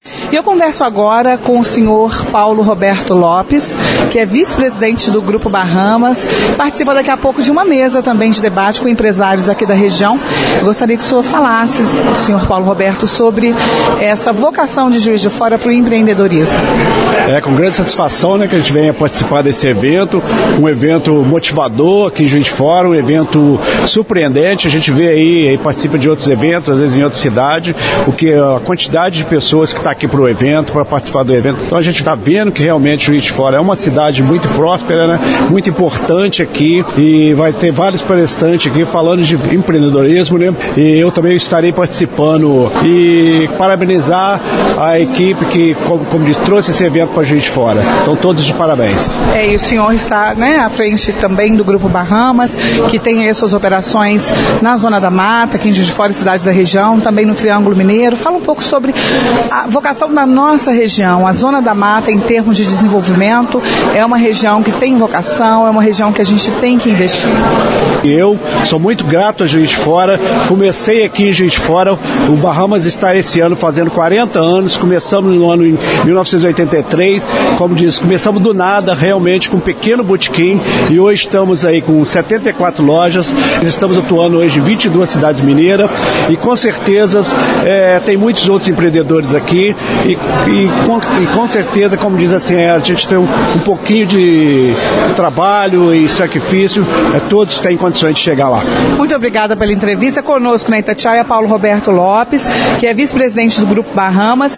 A reportagem da Itatiaia acompanhou a primeira tarde do evento e trouxe ao longo da programação as opiniões e avaliações de participantes.